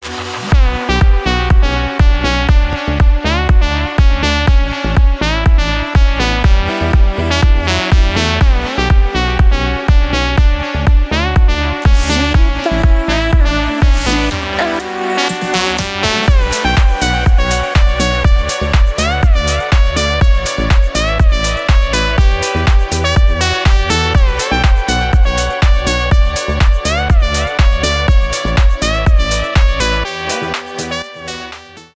клубные , house